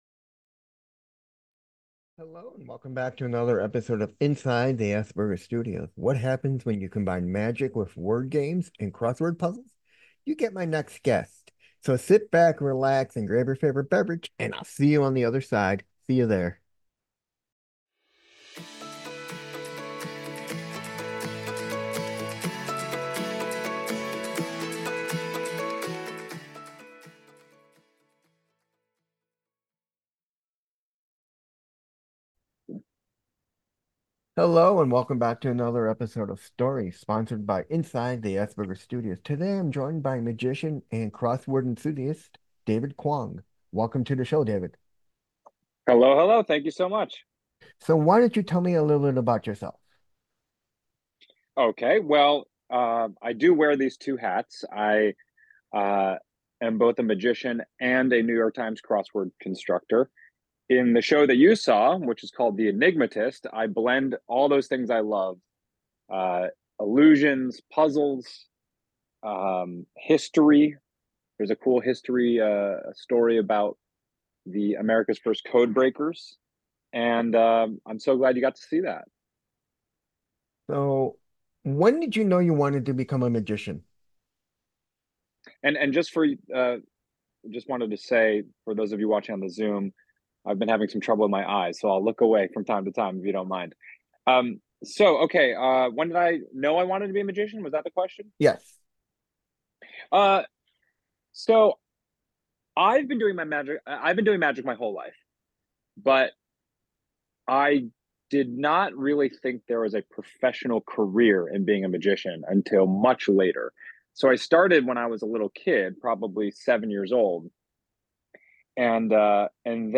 My special guest is David Kwong